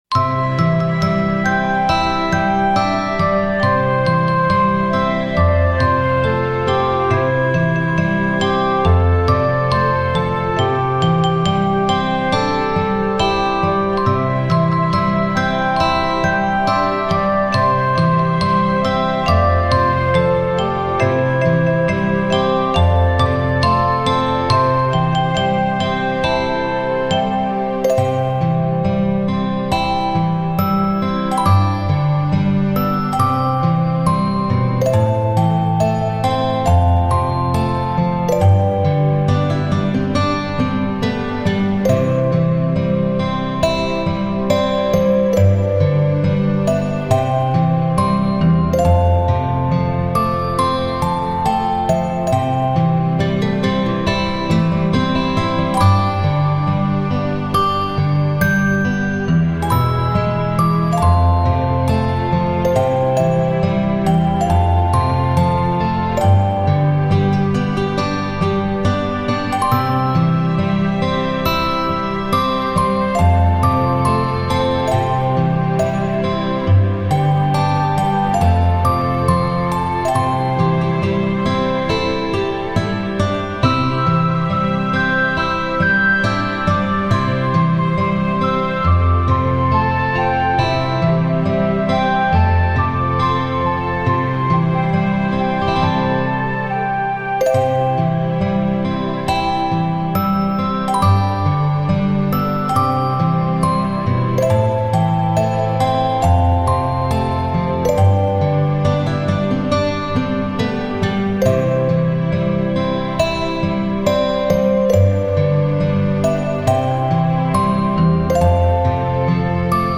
于是东方神秘的水晶音乐